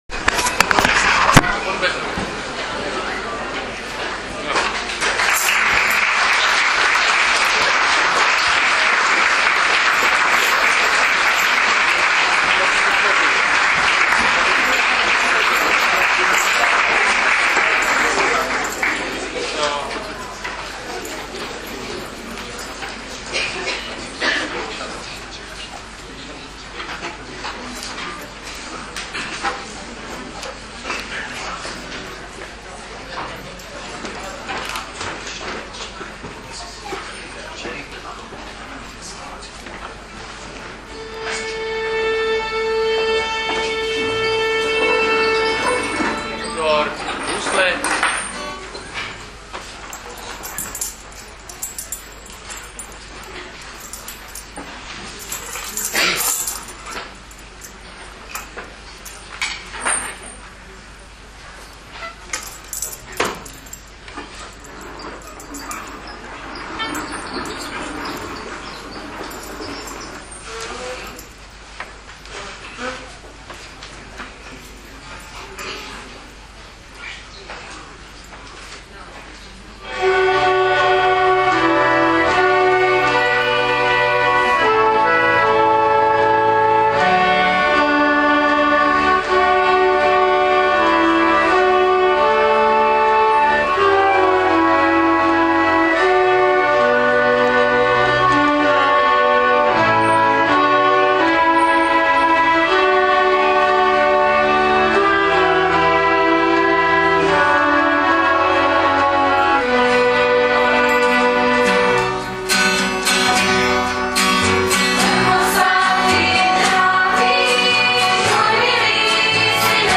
Zvukový záznam vánočního zpívání na tvrzi